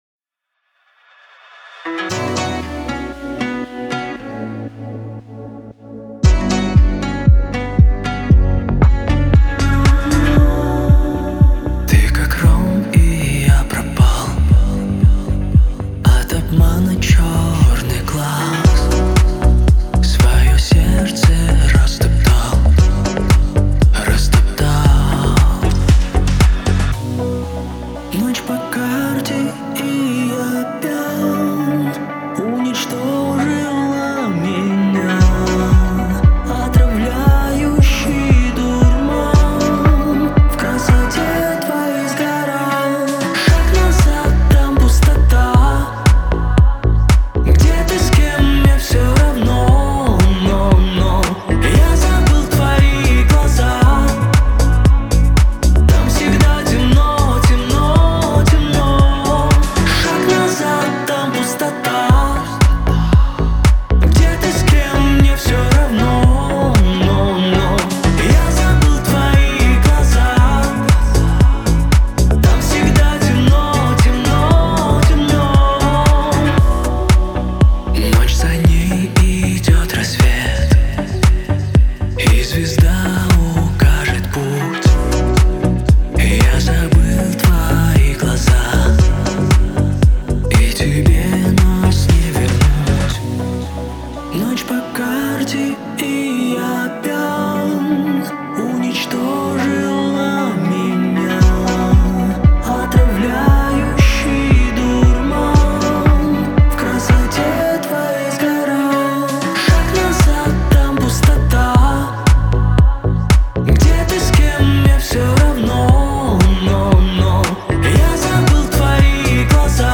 создавая атмосферу драйва и мотивации.